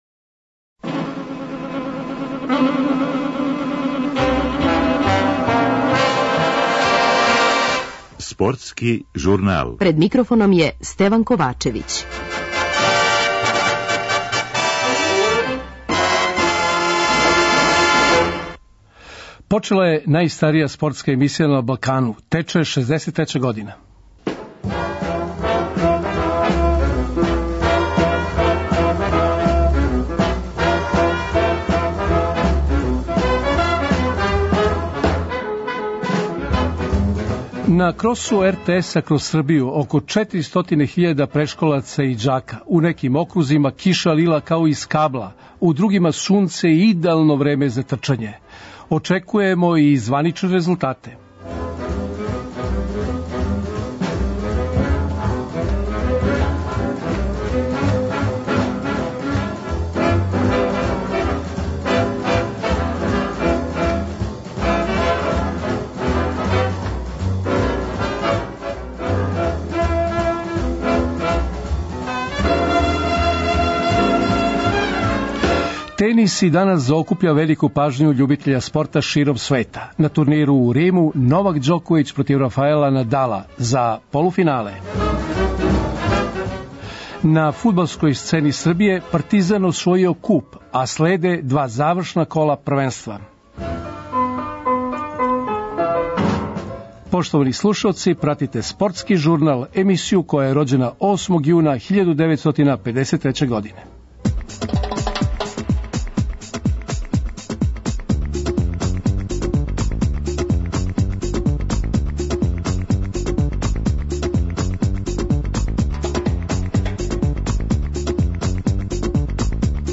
Очекујемо укључење министра просвете Срђана Вербића и први незванични резултат.